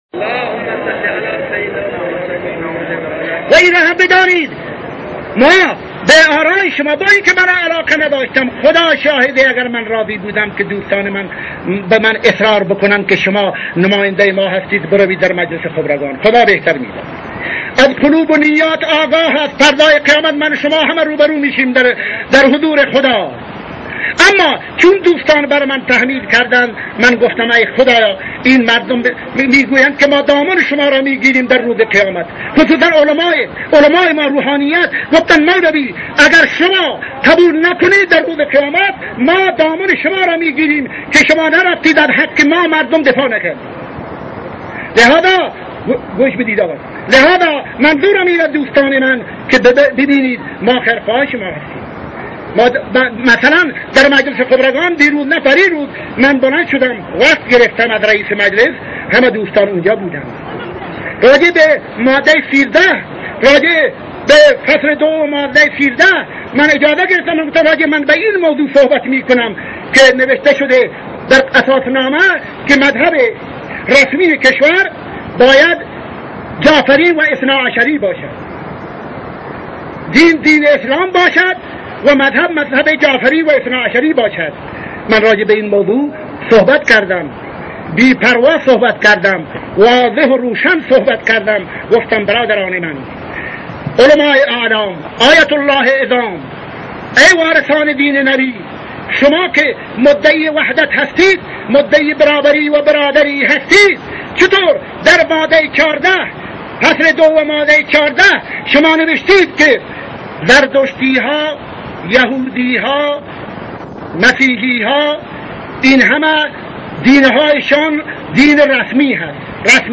سخنرانی مولانا عبدالعزيز ملازاده درشهريور 1358 در مورد نطق شدید اللحن وی در مجلس خبرگان ".....